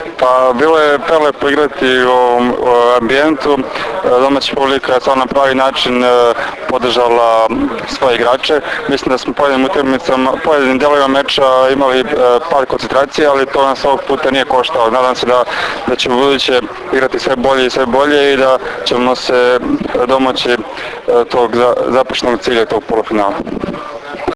IZJAVA MILOŠA TERZIĆA